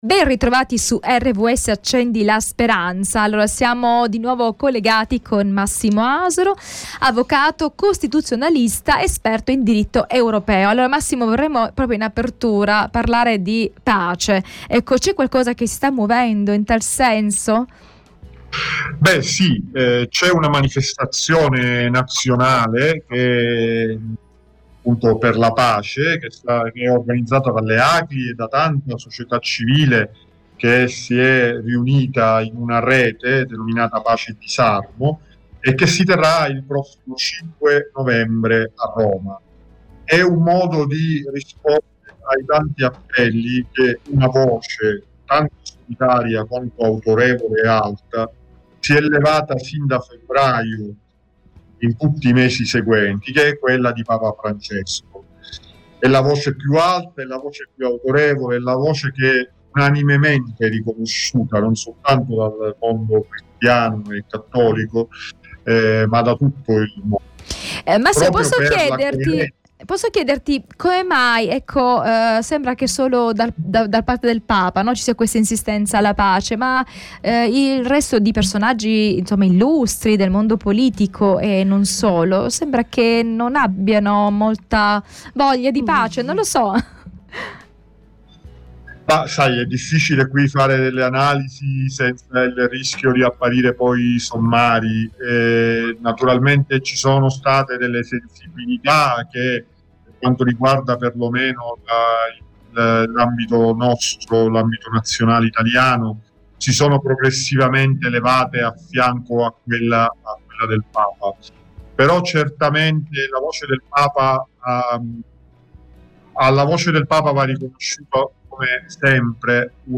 Segui il dialogo con l'avvocato